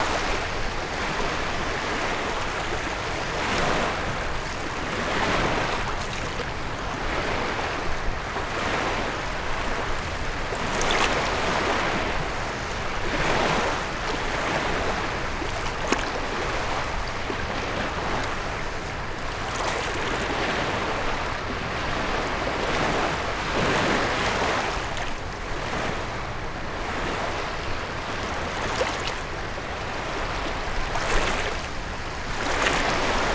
Water_movement_loop_2.ogg